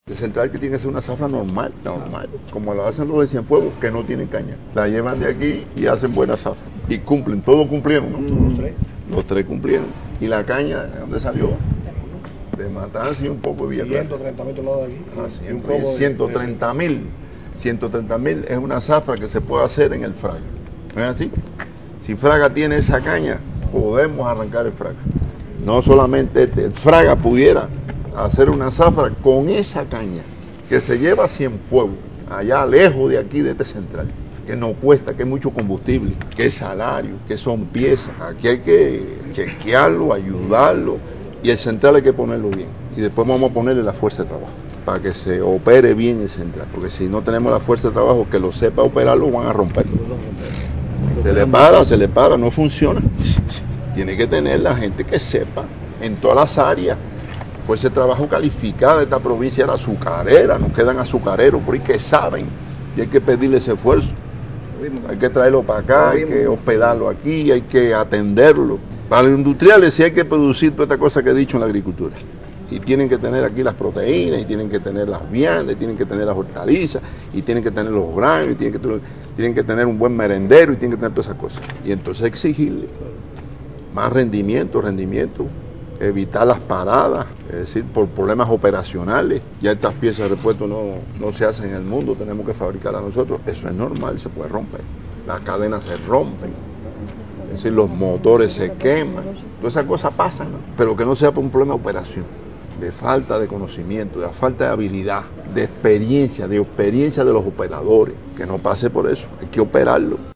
Declaraciones-de-Salvador-Valdes-Mesa.mp3